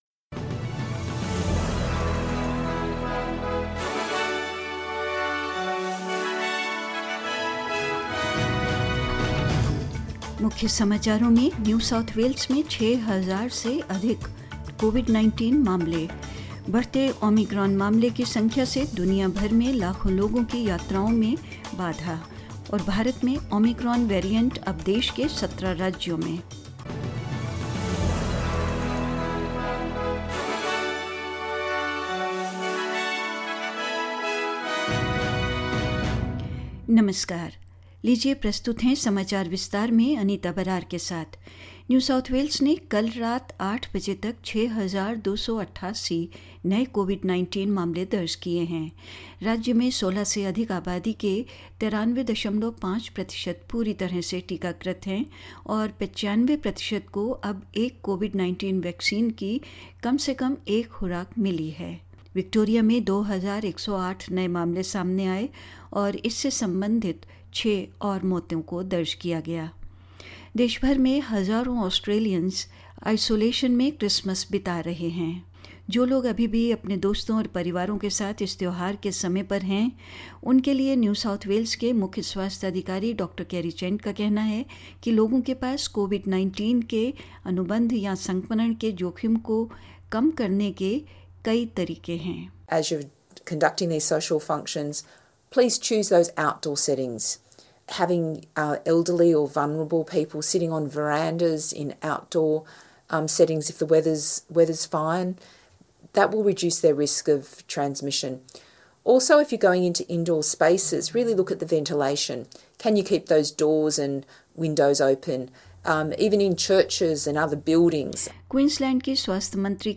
In this latest SBS Hindi news bulletin: More than 6000 new COVID-19 cases in New South Wales; Surging Omicron case numbers disrupt travel for millions around the the world; In India, Omicron variant spreads in 17 states and more news.